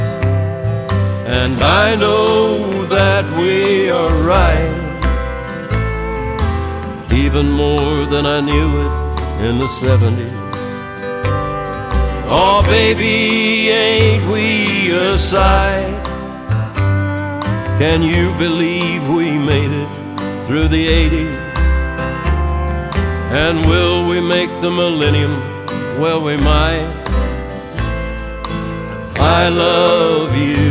Guitare
country music